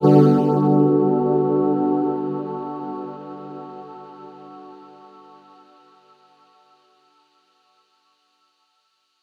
Chords_Gmaj_02.wav